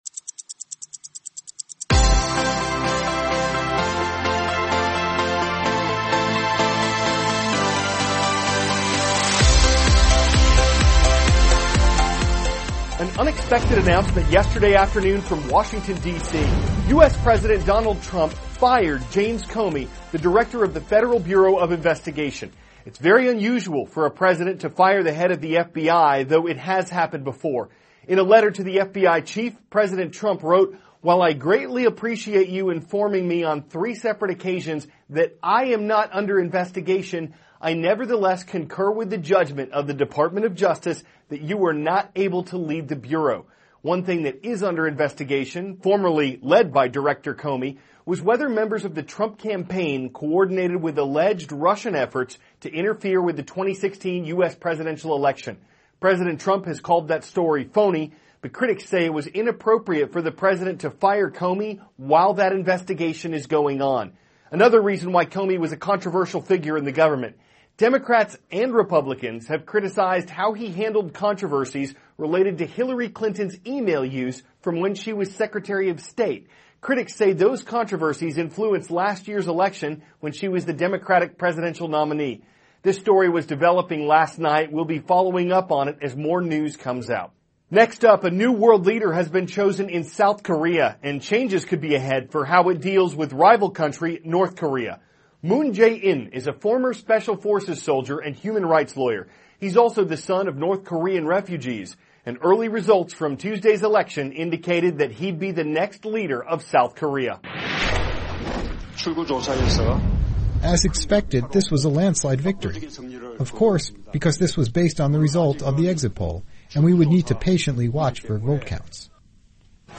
President Trump Fires FBI Chief; South Korea Elects New Leader; Liberated Civilians Describe Life Under ISIS THIS IS A RUSH TRANSCRIPT.